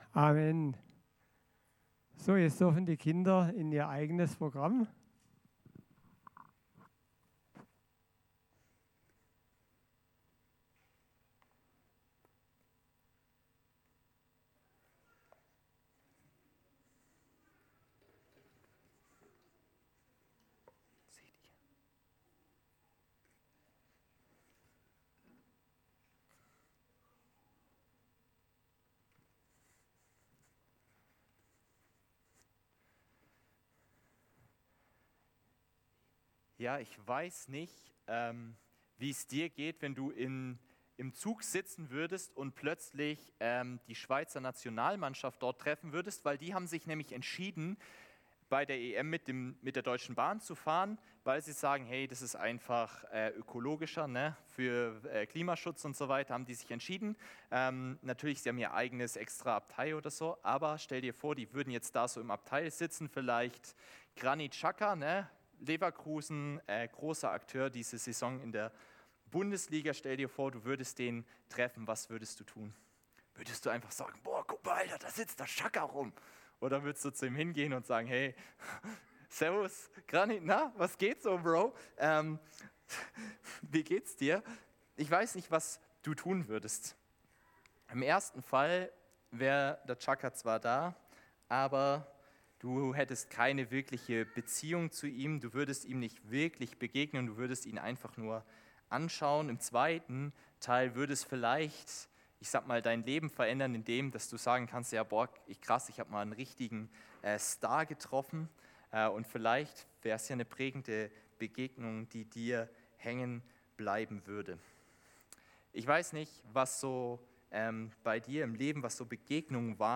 Predigt am 23.06.2024